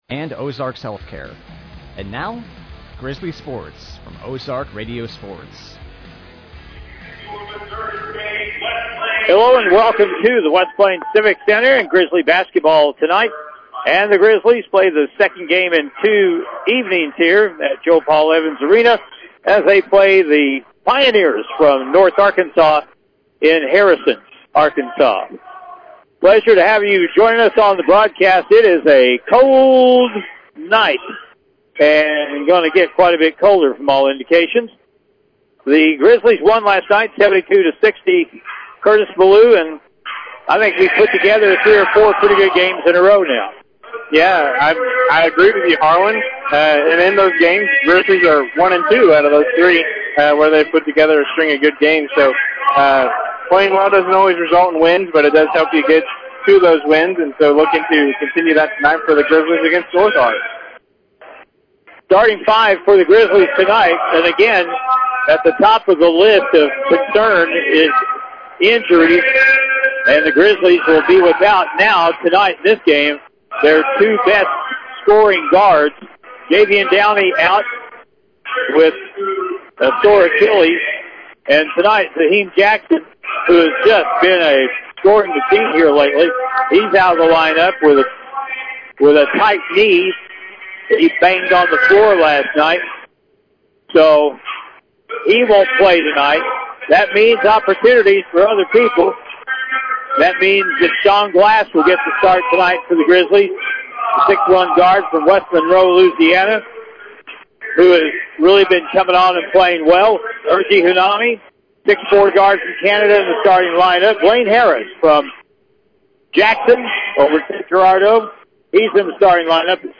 The Missouri State University – West Plains Grizzlies took on the North Arkansas College Pioneers at the West Plains Civic Center tonight, November 30, 2024.